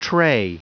Prononciation du mot tray en anglais (fichier audio)
Prononciation du mot : tray